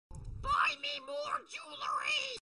Play, download and share buy me more jewelry! original sound button!!!!
smg4-sound-effects-buy-me-more-jewelry.mp3